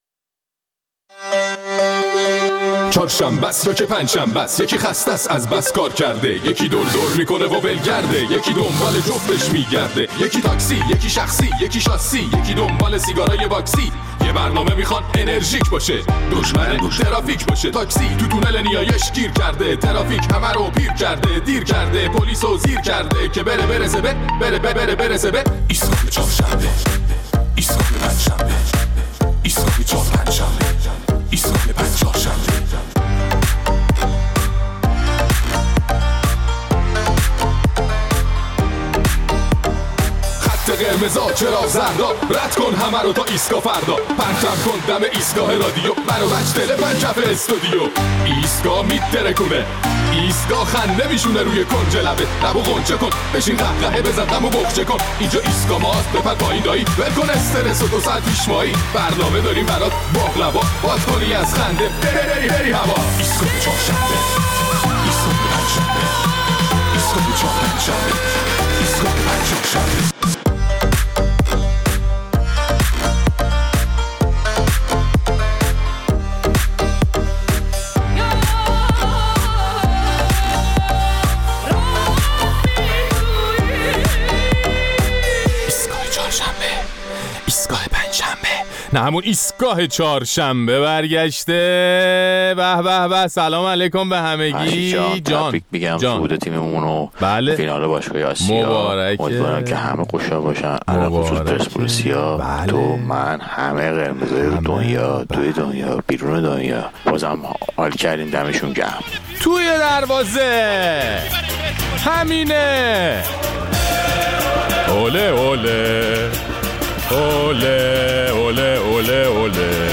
در این برنامه از شنوندگان‌مان درباره اجرایی شدن قانون منع به کارگیری بازنشستگان و نتایج آن پرسیده‌ایم و خودمان مفصلا به آن پرداخته‌ایم.